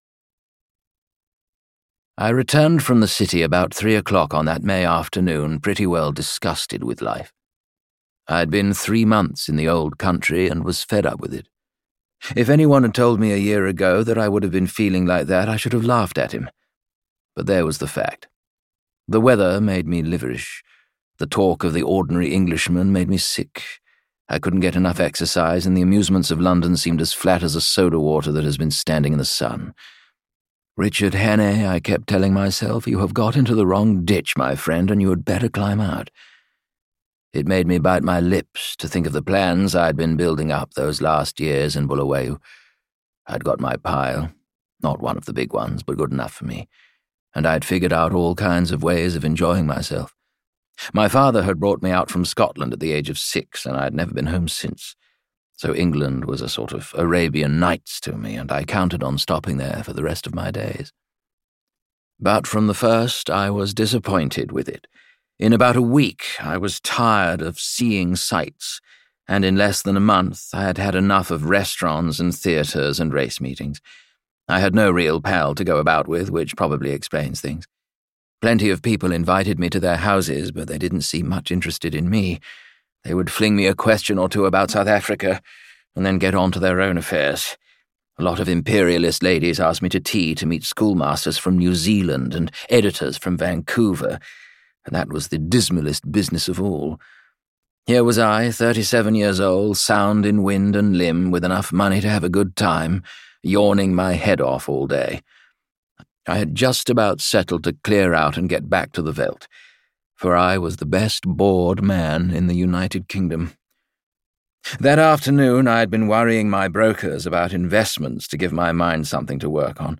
The Thirty-Nine Steps audiokniha
Ukázka z knihy
It is read by Rupert Degas, whose ‘quick-change brilliance’ in Patrick Barlow’s long-running stage adaptation was so highly praised.
• InterpretRupert Degas